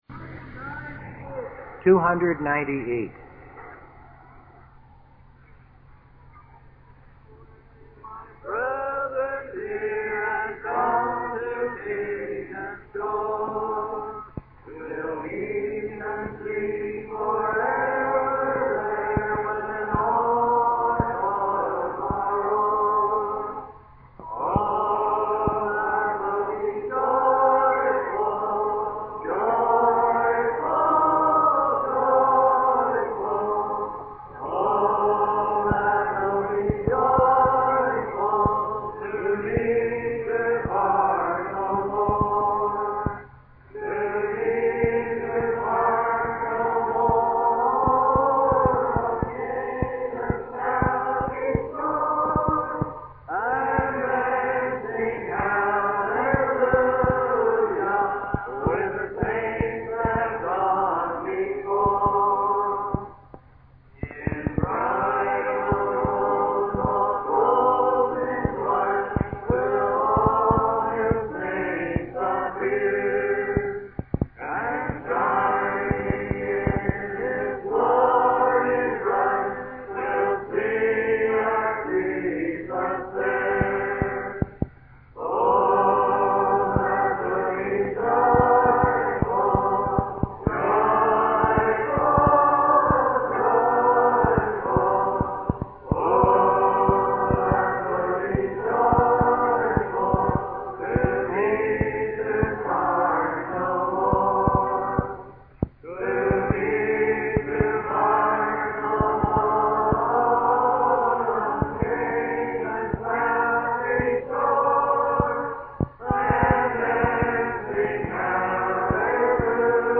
From: Walla Walla Conference: 1992